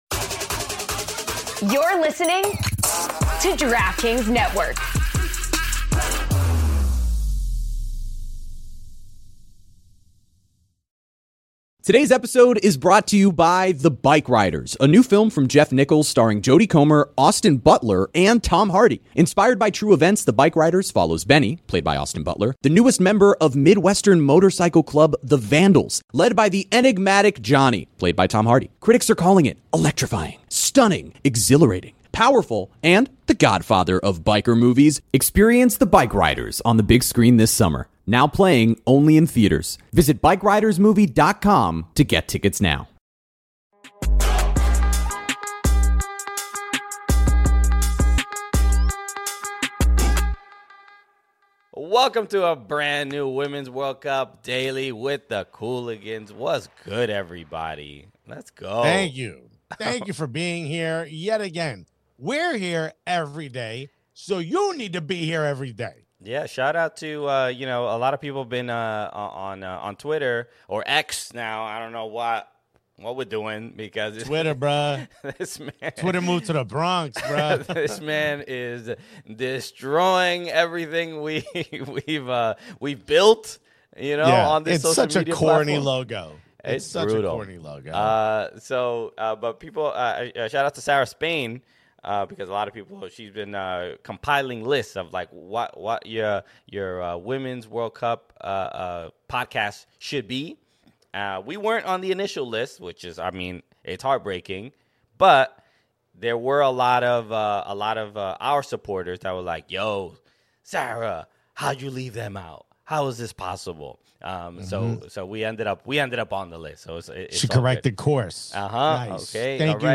Brandi Chastain interview